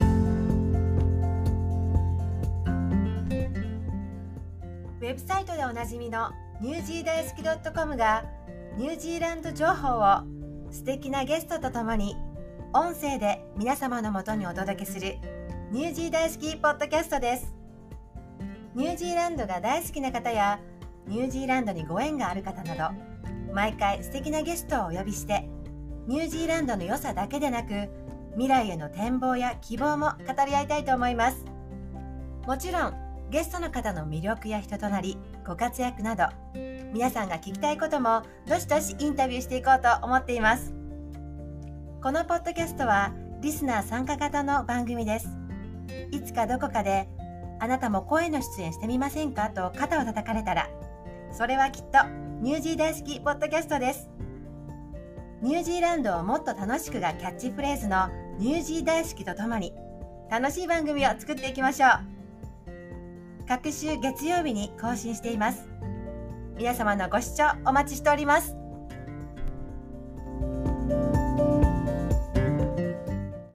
WEBSITEでお馴染みの ニュージー大好き.comが、ニュージーランド情報を、素敵なゲストと共に、音声で皆様のもとにお届けする、NZ Daisuki PODCASTです。